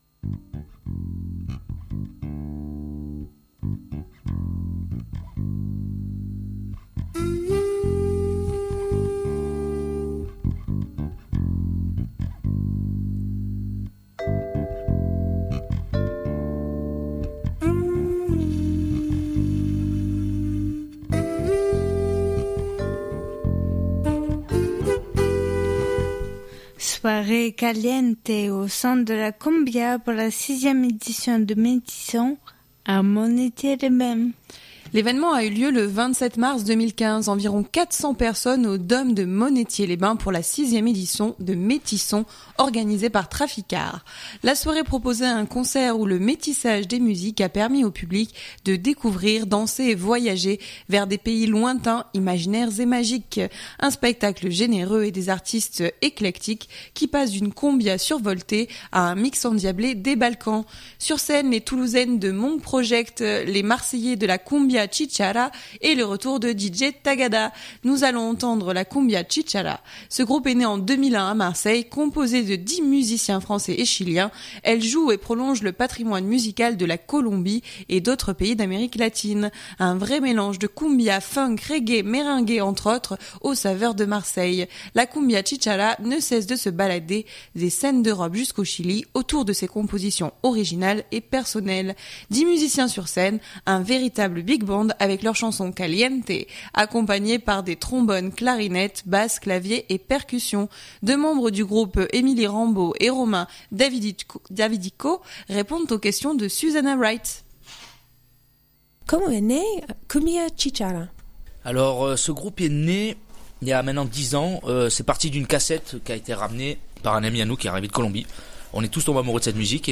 Ce groupe est né en 2001 à Marseille, composée de dix musiciens français et chiliens, elle joue et prolonge le patrimoine musical de la Colombie et d’autres pays d’Amérique Latine. Un vrai mélange de Cumbia, Funk, Reggae, Merengue, entre autres, aux saveurs de Marseille.
Dix musiciens sur scène, un véritable Big Band, avec leurs chansons « caliente » accompagnées par des trombones, clarinettes, basse, clavier et percussions.